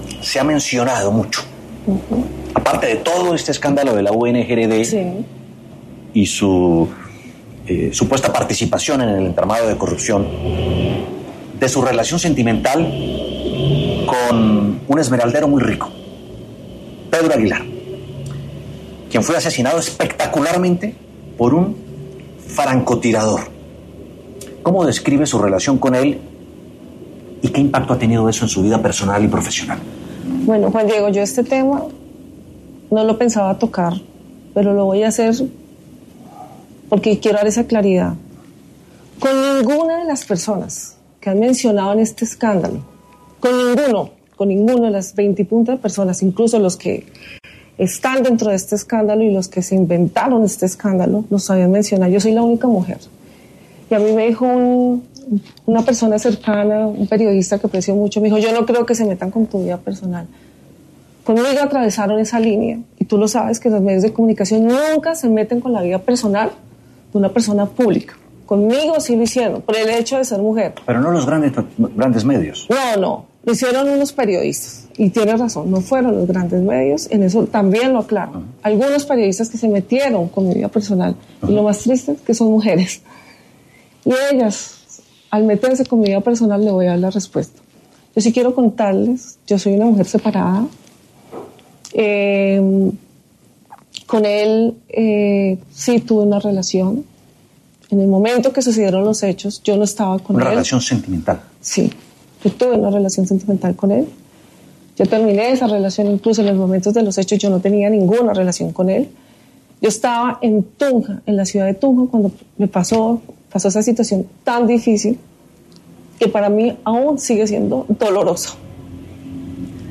En conversación exclusiva para La W, Sandra Ortiz habló sobre su relación sentimental con el esmeraldero asesinado en Bogotá, respondiendo a los señalamientos de algunos medios.